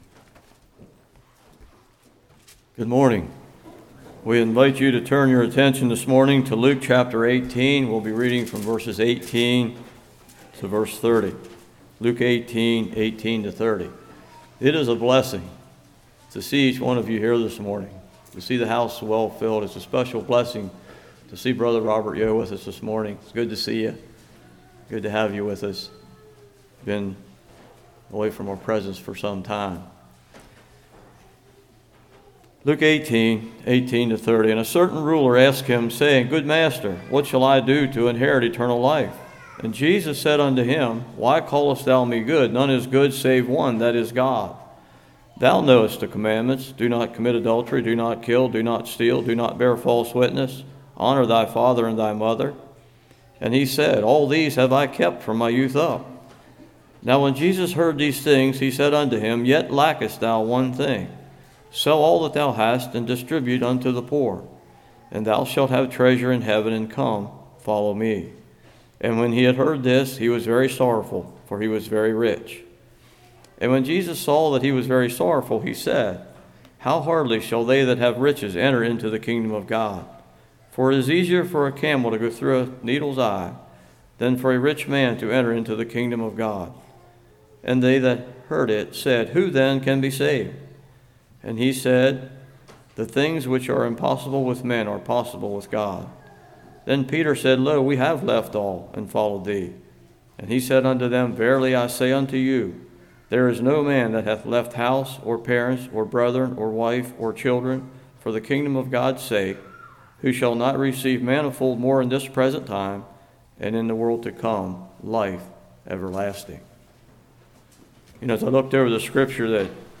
Luke 18:18-30 Service Type: Morning Fulfill